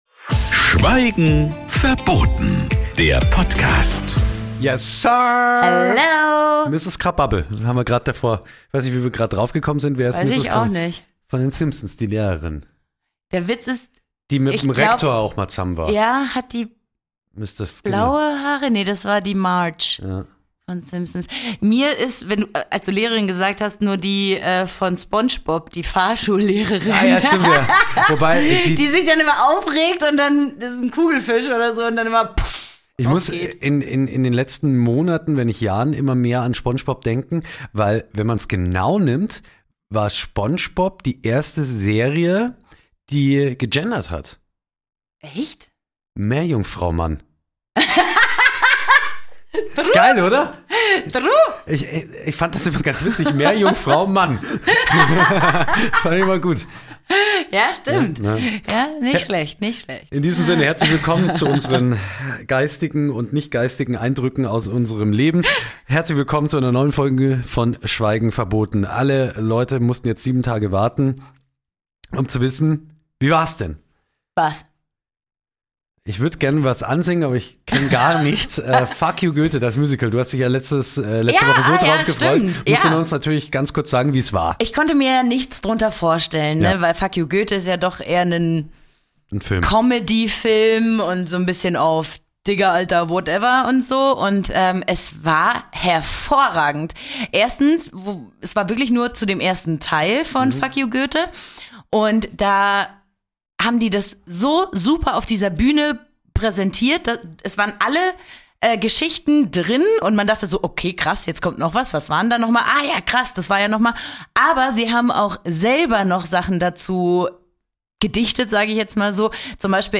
Sie talken wöchentlich am Freitag so, wie ihnen der Schnabel gewachsen ist.